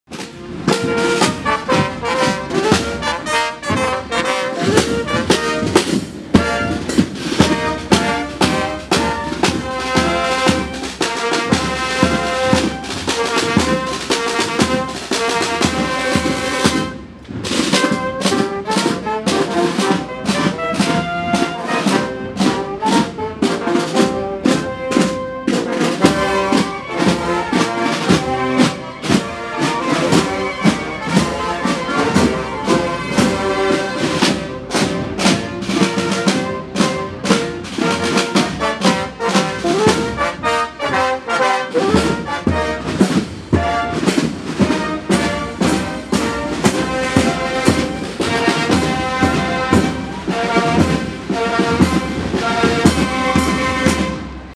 Desfile de compañías de “Armaos” - 250 Aniversario
Los tercios romanos de estas localidades realizaron un desfile por las calles de Totana, desde la sede de la Hermandad totanera, hasta la plaza de la Constitución, donde los distintos grupos participantes realizaron sus respectivas puestas en escena más peculiares, acompañados de su música nazarena más característica.
Al término del acto los responsables de los diferentes tercios se intercambiaron obsequios conmemorativos coincidiendo con este evento cultural y social, que congregó a numeroso público en la plaza de la Constitución.